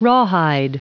Prononciation du mot rawhide en anglais (fichier audio)
Prononciation du mot : rawhide